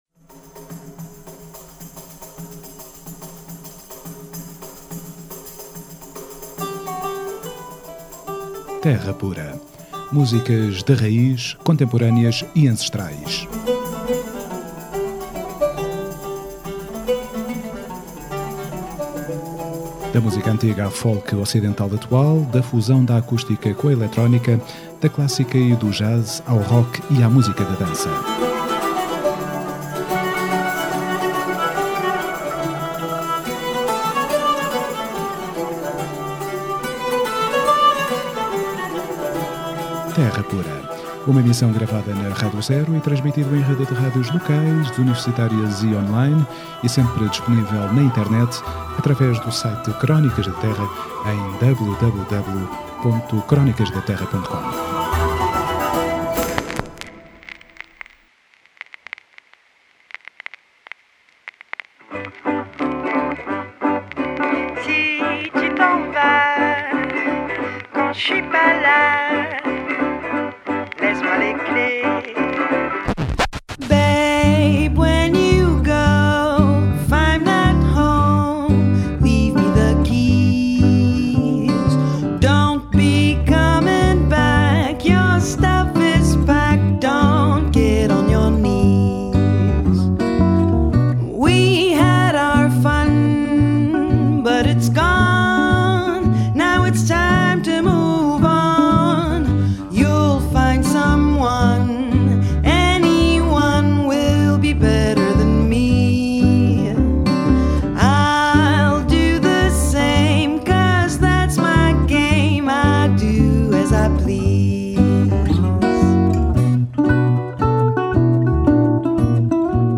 Terra Pura 20JAN14: Entrevista Nobody’s Bizness